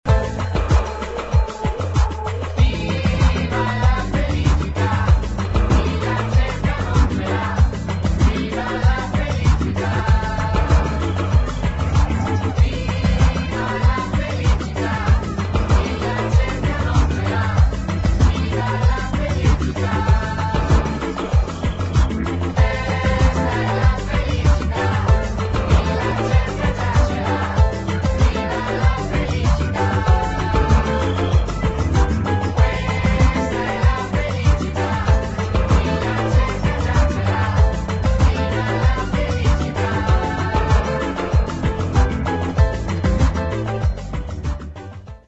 [ SOUNDTRACK / LOUNGE / HOUSE ]